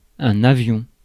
Ääntäminen
IPA : /kaɪt/